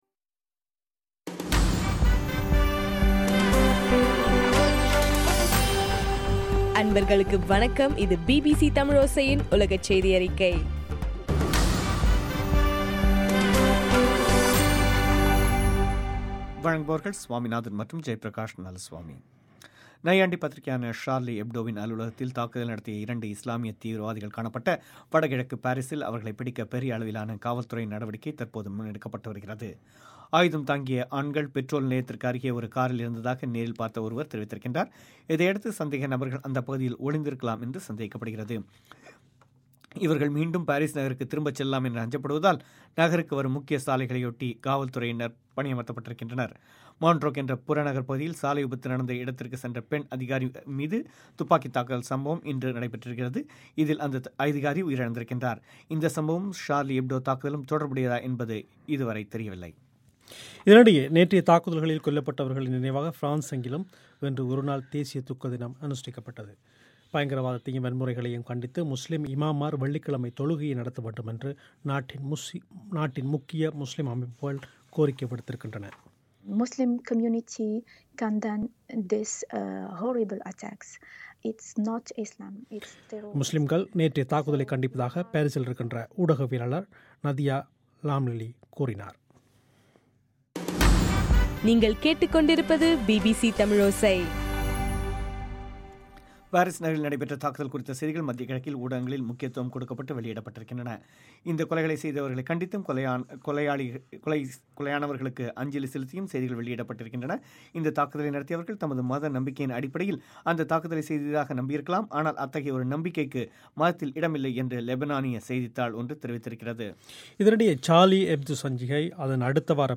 ஜனவரி 8 பிபிசி தமிழோசையின் உலகச் செய்திகள்